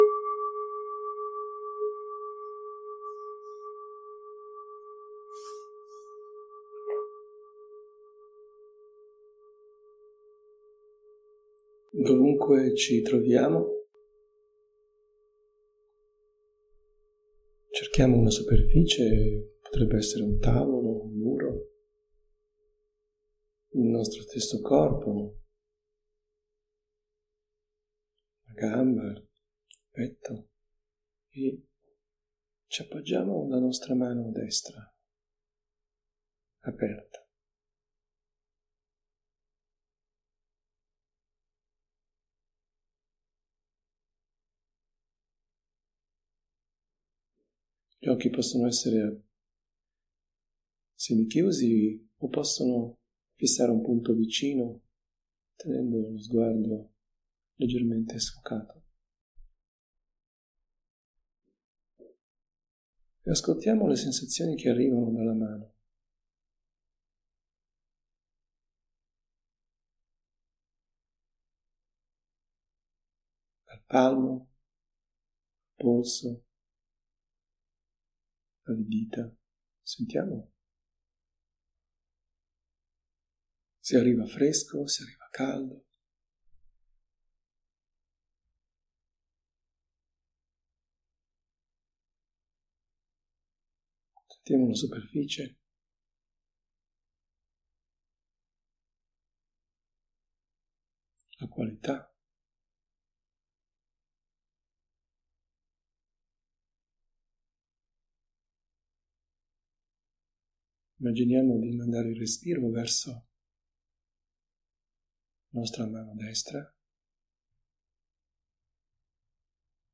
meditazioni guidate